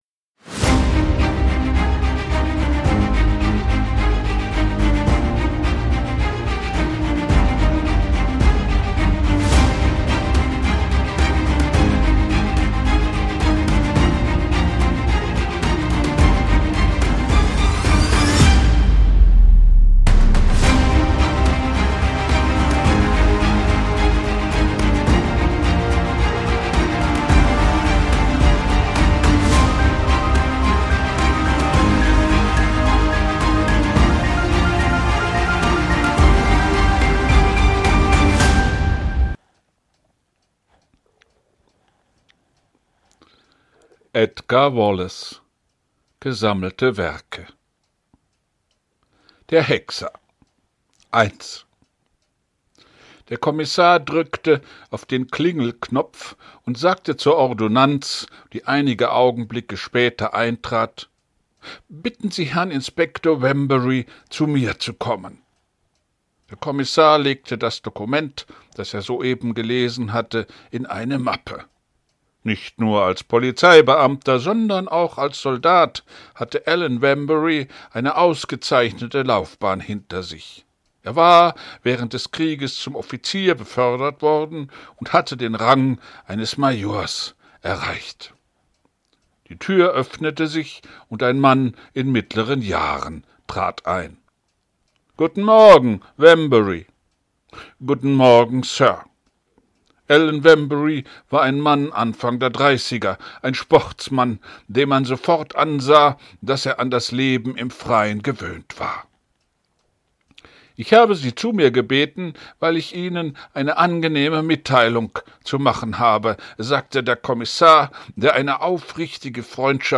ich lese vor wallace der hexer 1